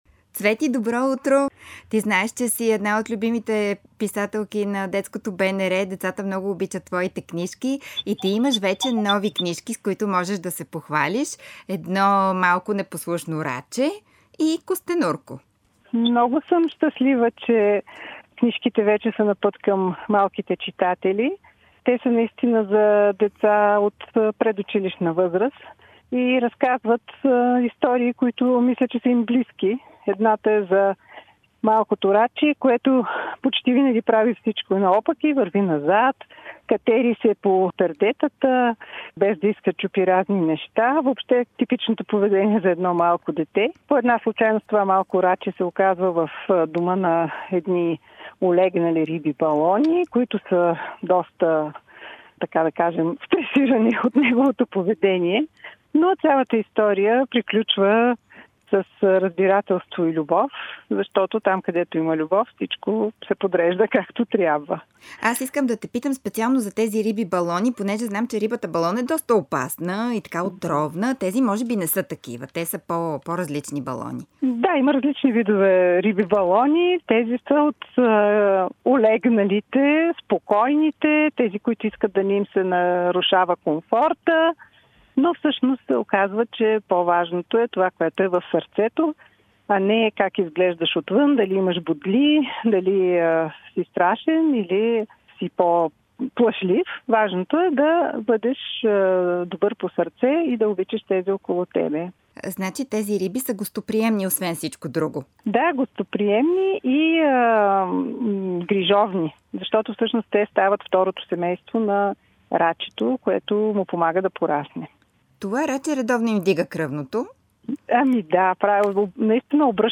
разговора